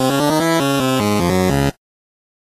nes-rygar-death_26083.mp3